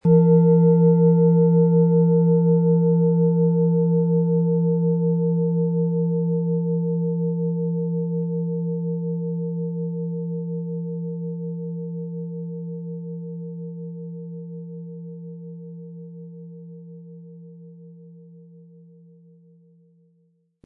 Hopi Herzton
Um den Original-Klang genau dieser Schale zu hören, lassen Sie bitte den hinterlegten Sound abspielen.
MaterialBronze